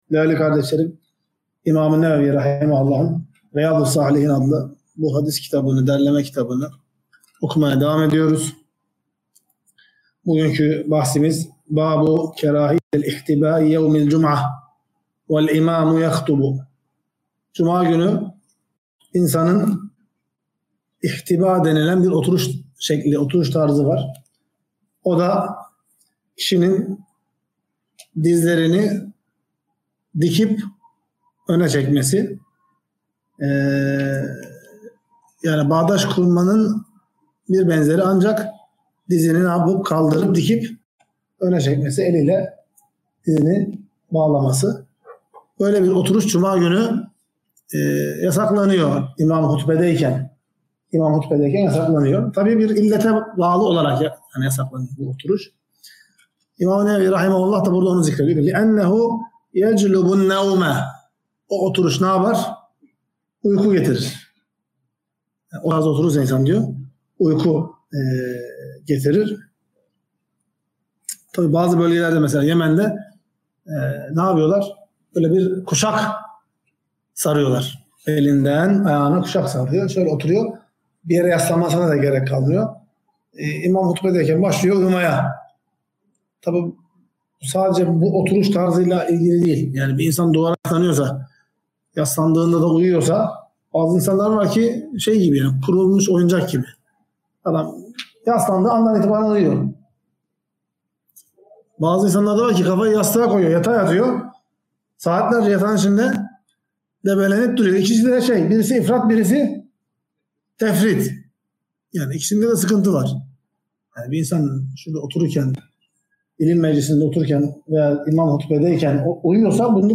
Ders - 59.